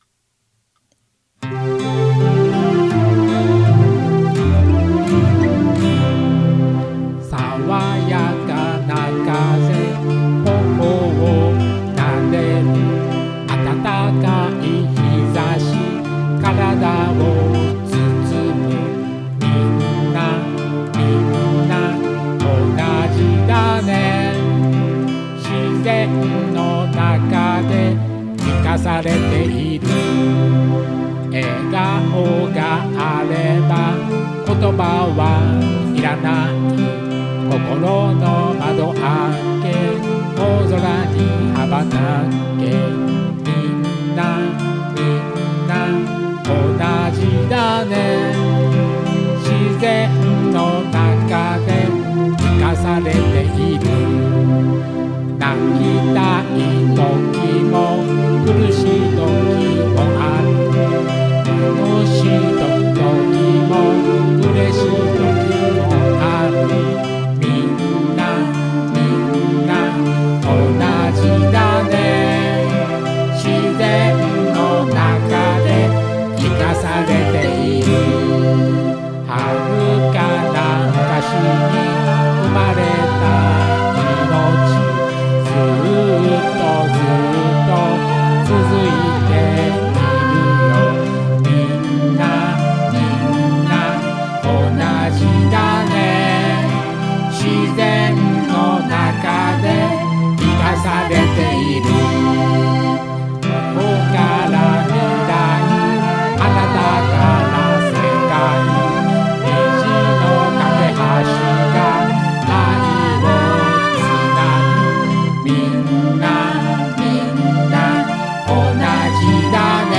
歌入りフル音源 をダウンロード